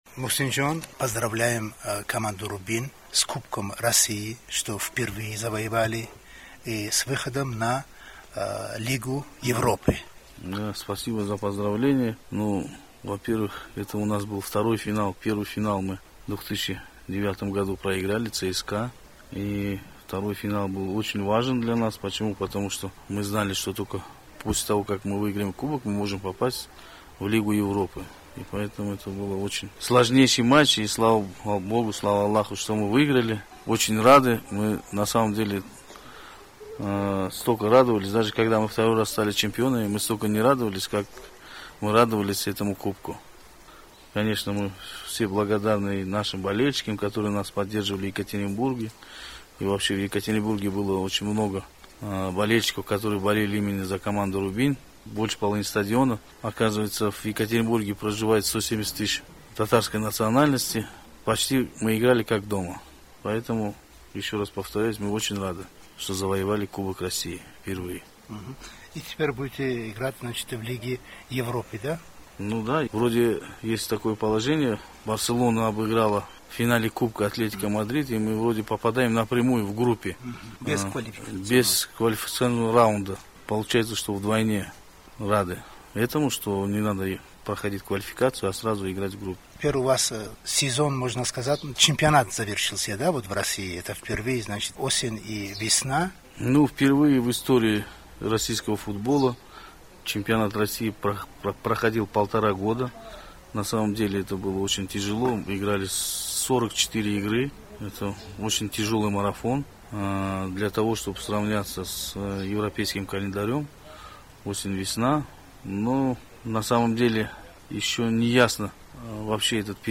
Интервью Мухсина Мухаммадиева радио Озоди